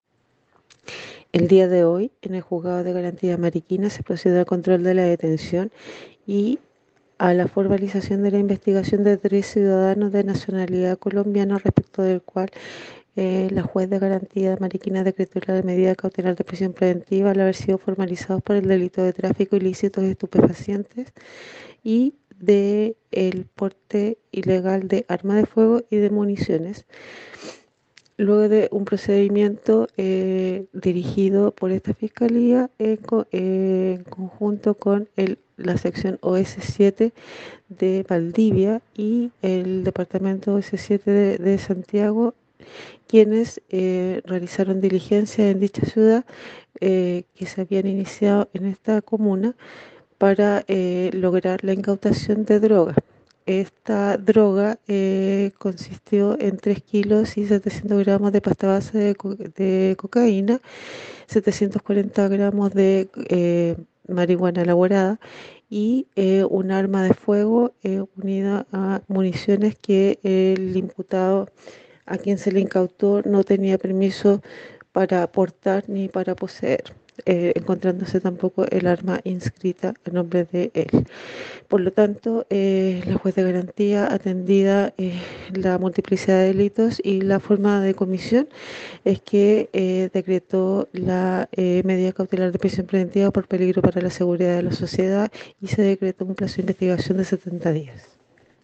fiscal subrogante de Mariquina, Carmen Gómez, sobre la formalización efectuada hoy por la Fiscalía a tres imputados de nacionalidad colombiana por el delito de tráfico de drogas, quienes quedaron en prisión preventiva